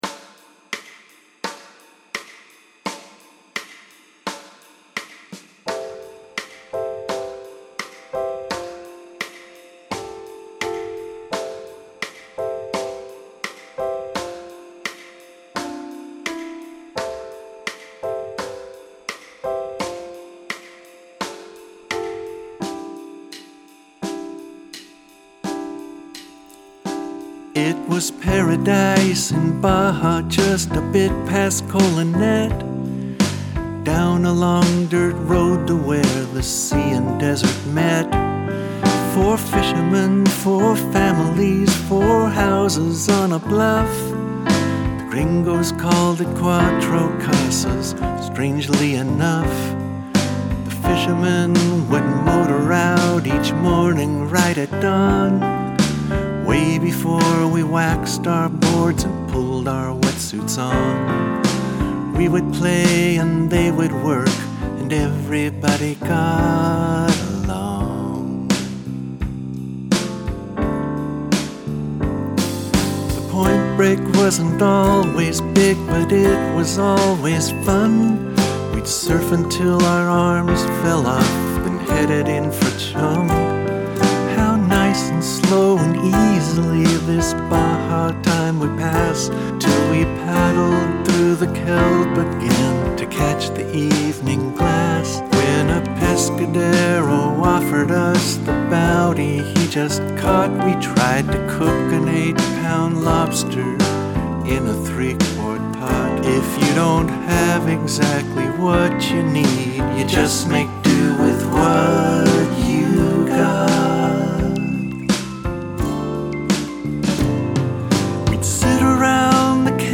Cuatros Ballad mp3.mp3